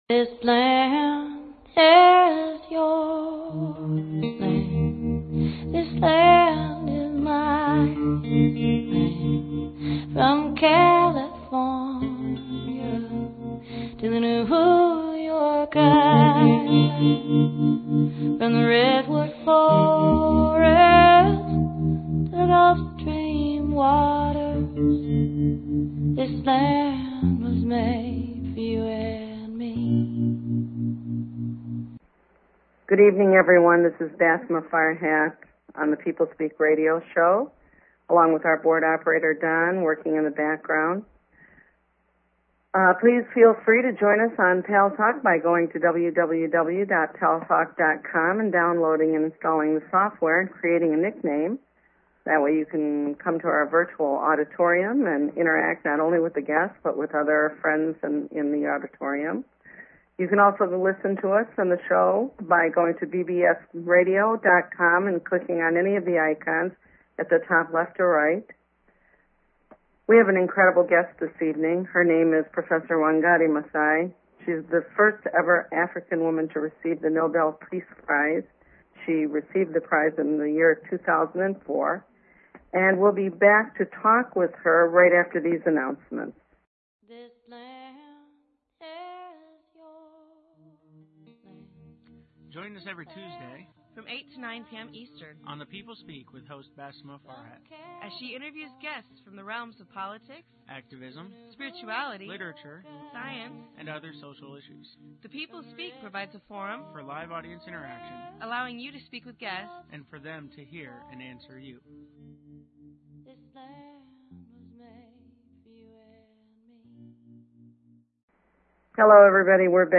Guest, Nobel Peace Laureate Wangari Mathai
The People Speak with guest Nobel Peace Laureate Wangari Mathai